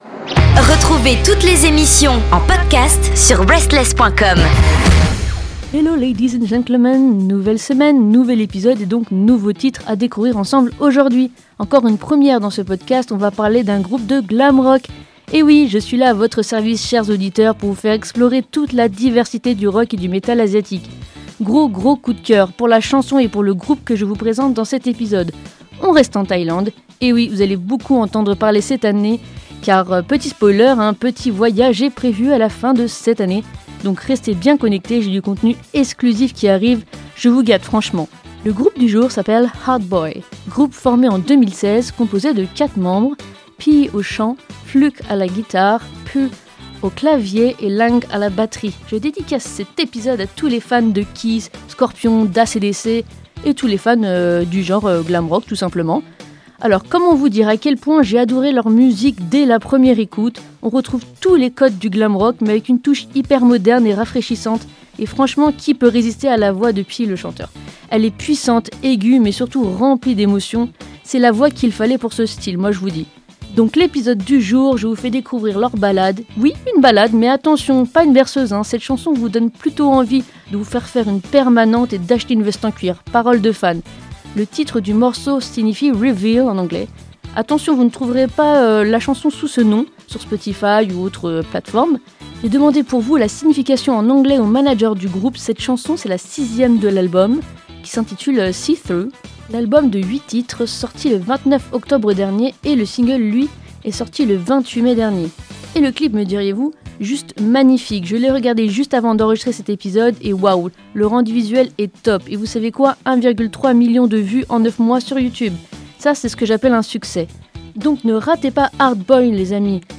Un vent de glam rock venu de Thaïlande !
Je vous fais découvrir le groupe HARD BOY et mon titre coup de cœur « เผย » ou « Reveal » en anglais, entre riffs puissants, claviers flamboyants et voix pleine d’émotion.